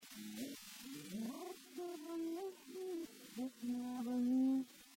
GRUPPO DI ANGELI